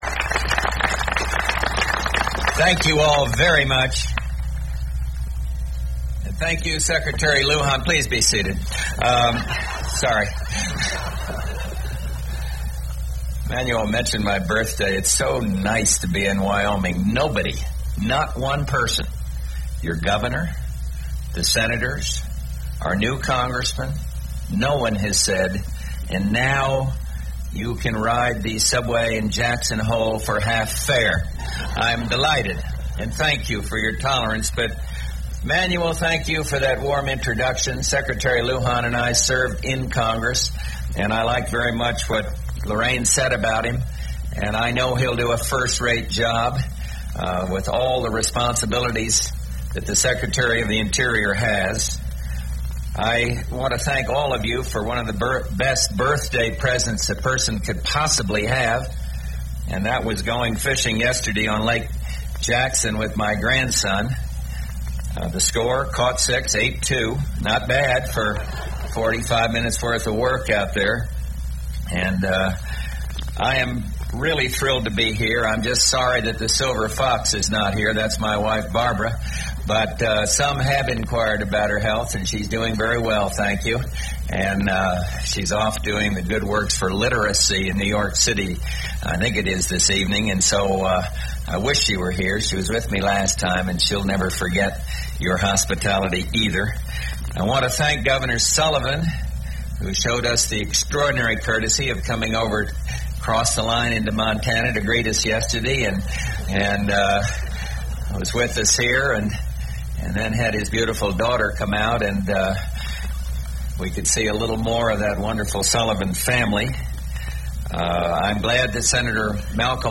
U.S. President George Bush addresses an audience in Kelly, Wyoming on wildlife and environmental issues